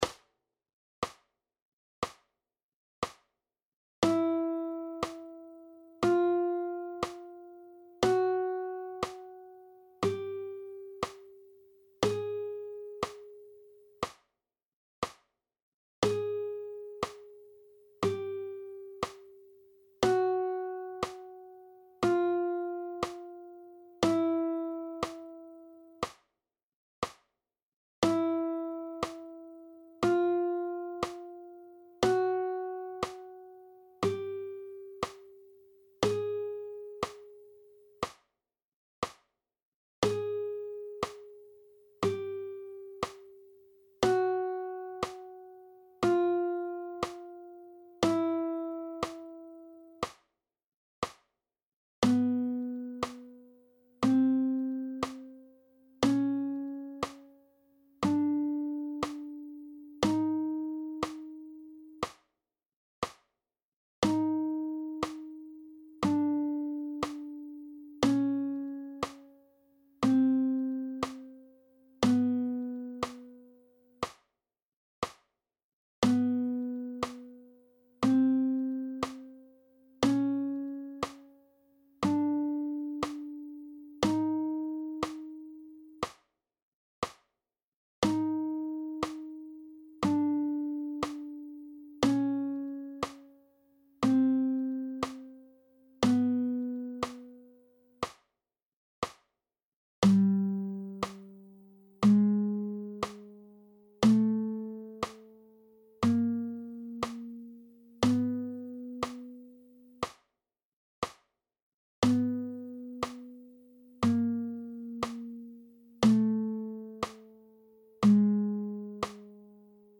Töne Saite 1 – 6, chromatisch auf- und abwärts mit # – und b – Vorzeichen: PDF